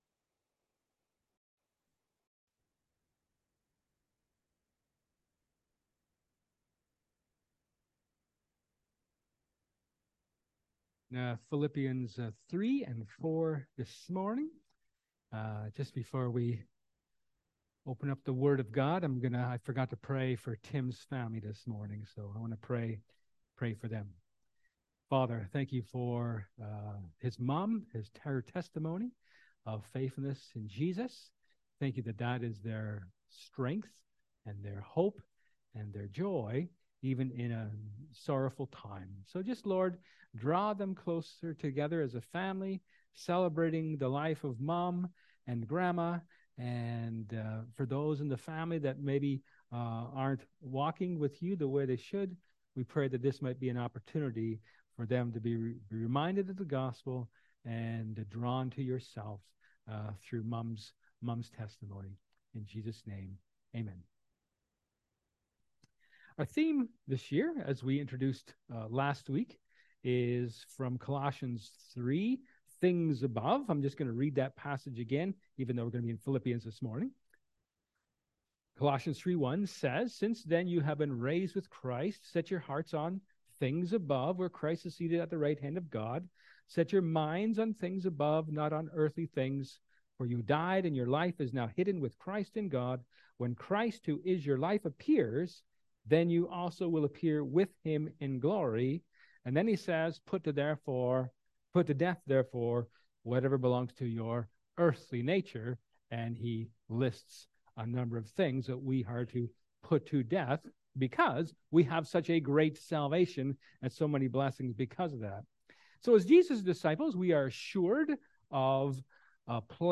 1 Corinthians 11:17-34 Service Type: Sermon